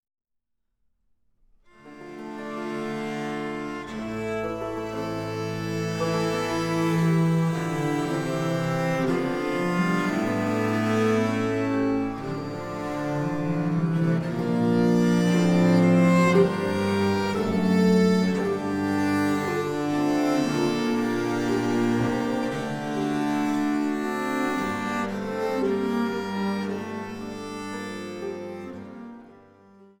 Gambenmusik - zahlreiche Ersteinspielungen
Diskant- und Bassgambe